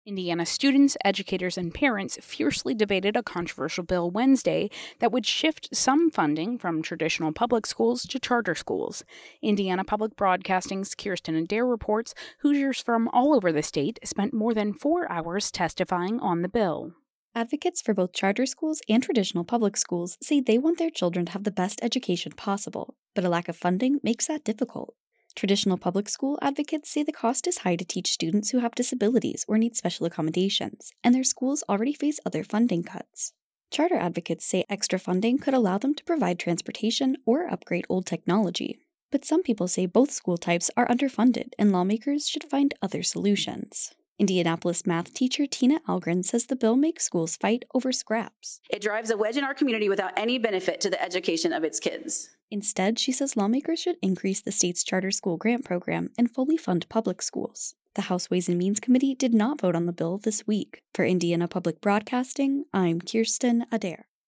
testimony-on-sb-518-web.mp3